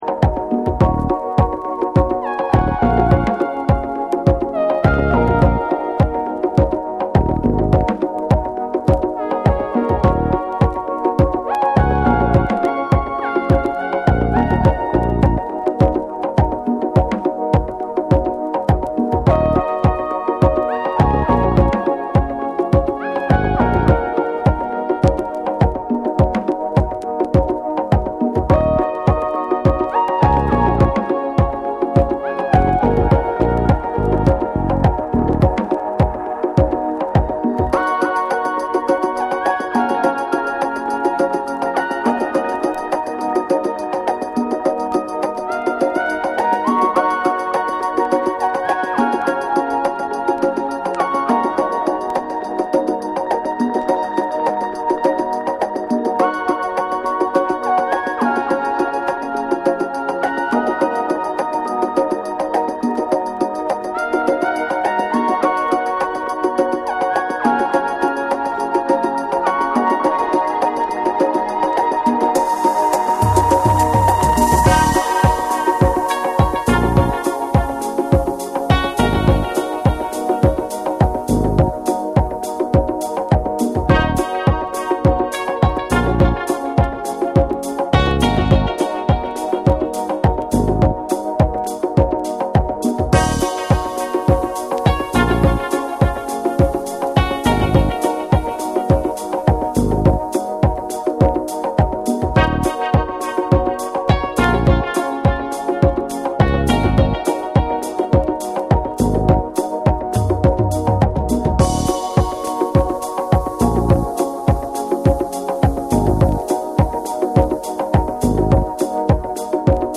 TECHNO & HOUSE / ORGANIC GROOVE / NEW RELEASE(新譜)